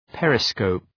Προφορά
{‘perı,skəʋp}